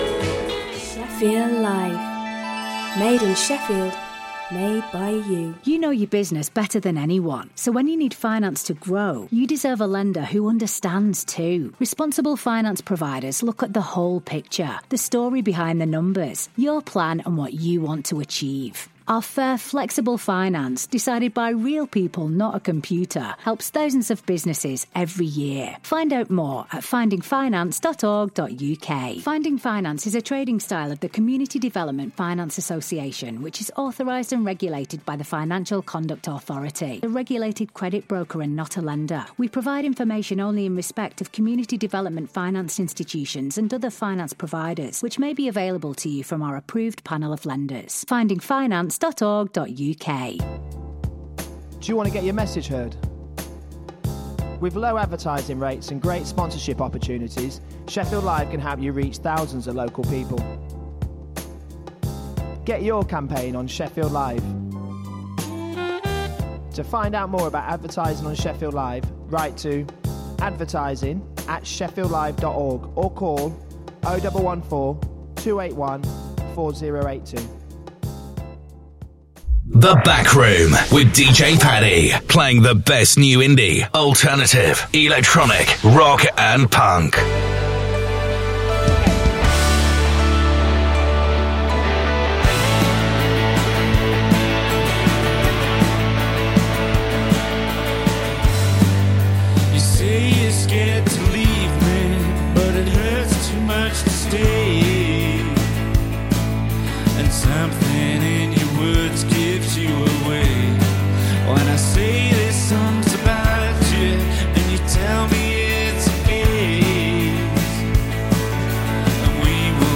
playing the best in new indie , electro, EDM, and punk